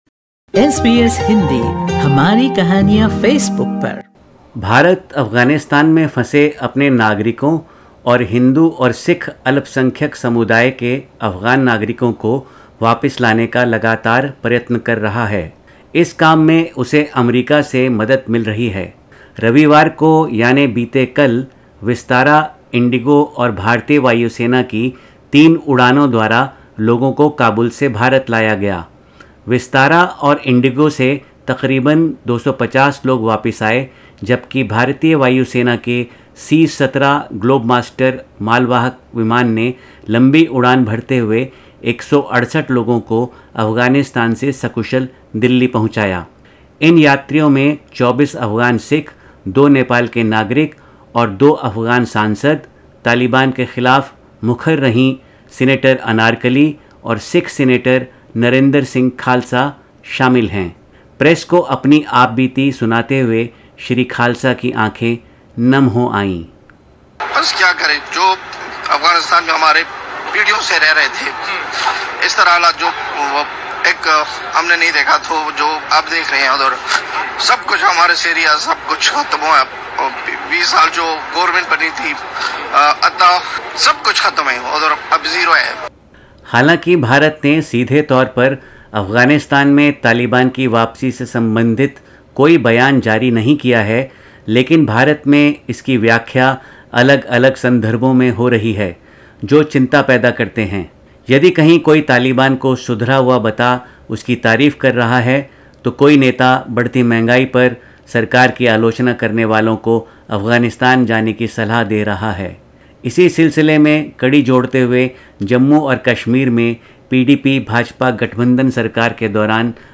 sbs_hindi_news_bulletin_august_23.mp3